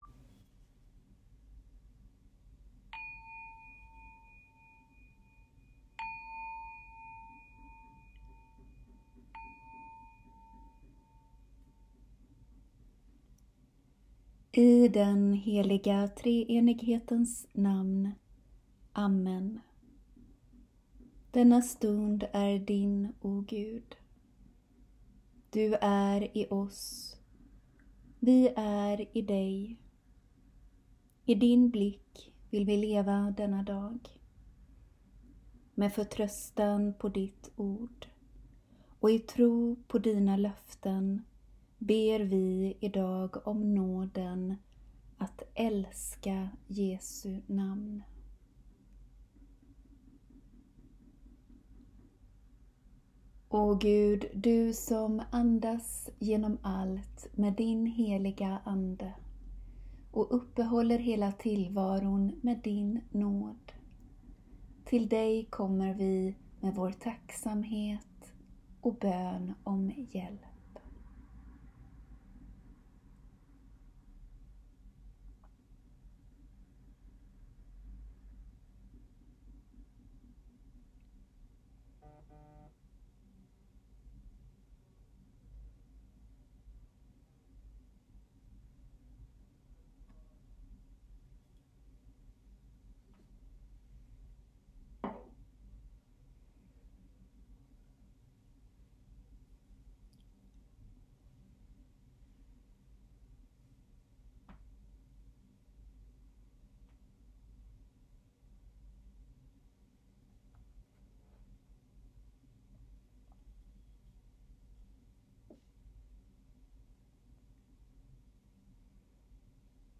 Bön vid dagens början (3 min)
Ljudfilen är inspelad i en stuga vid havet en morgon när det stormade och snöregnet vräkte ner. Det hörs också i bönen och kanske kan det få påminna om vad psalmisten skriver i Psaltaren 147 om att både snö och hagel, storm och köld ska prisa Herren.